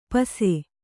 ♪ pase